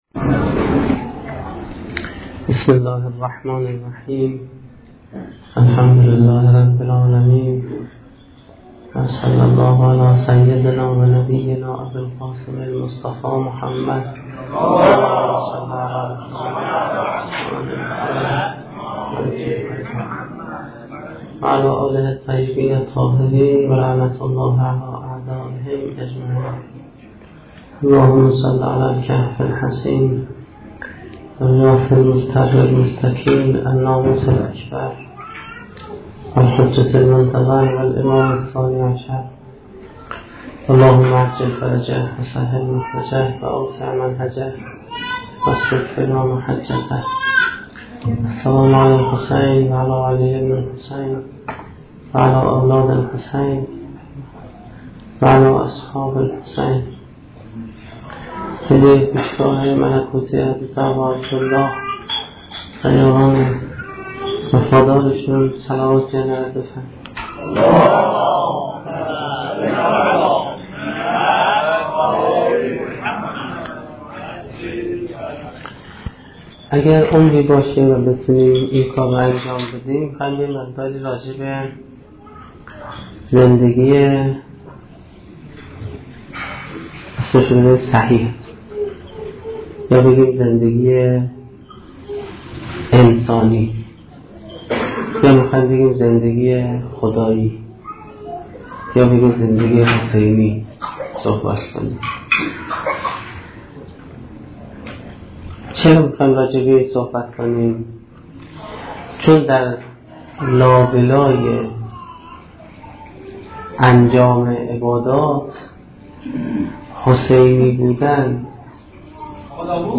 سخنرانی اولین شب دهه محرم1435-1392